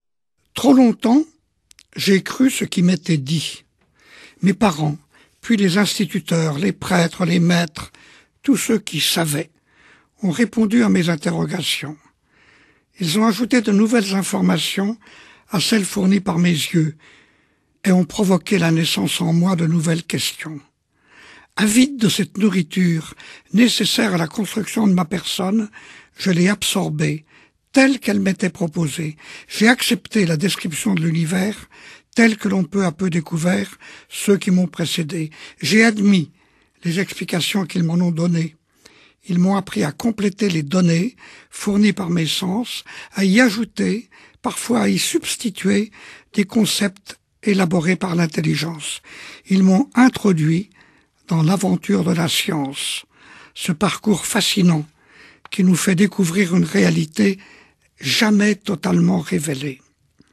0% Extrait gratuit Dieu ? de Albert Jacquard Éditeur : Coffragants Paru le : 2009 Lu par l'auteur.